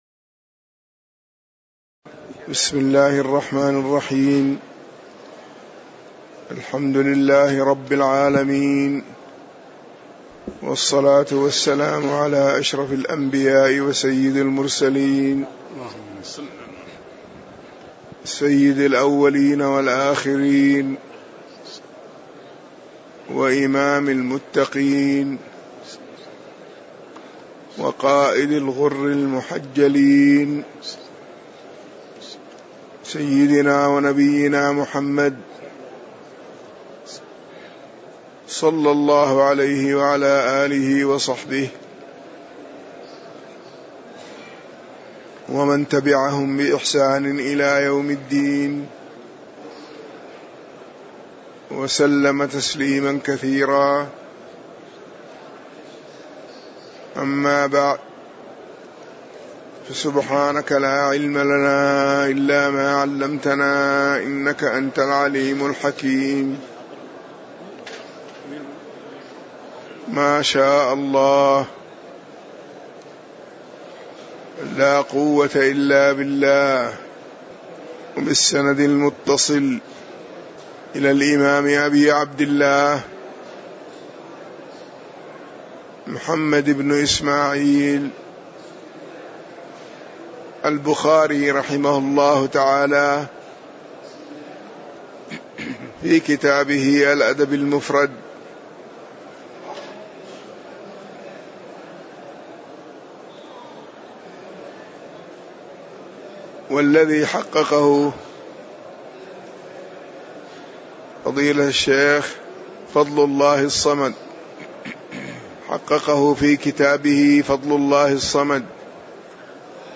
تاريخ النشر ٢ ربيع الثاني ١٤٣٨ هـ المكان: المسجد النبوي الشيخ